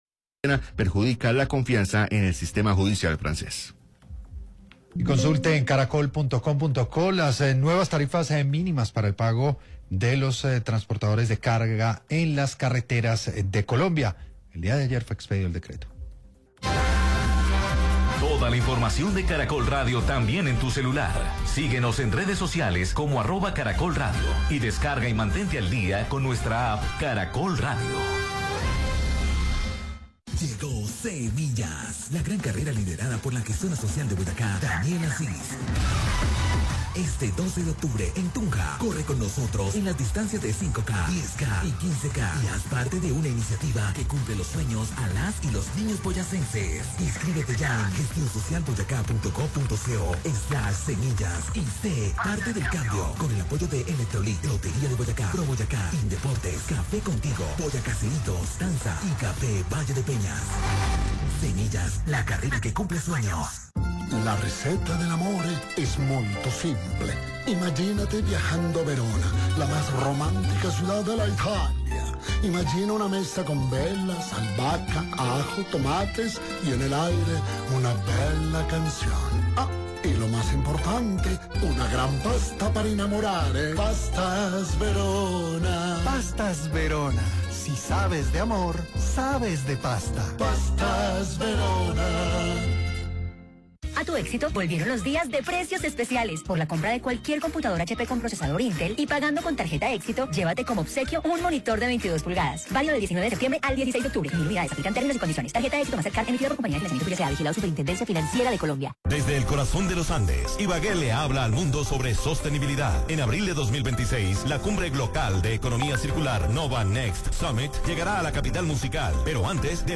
En entrevista con 6AM de Caracol Radio, la senadora Angélica Lozano, integrante de la Comisión Cuarta de Asuntos Económicos del Congreso, calificó como una buena noticia que el Presupuesto General de la Nación 2026 se haya aprobado en primer debate sin necesidad de un decreto.